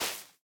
Minecraft Version Minecraft Version latest Latest Release | Latest Snapshot latest / assets / minecraft / sounds / block / big_dripleaf / step2.ogg Compare With Compare With Latest Release | Latest Snapshot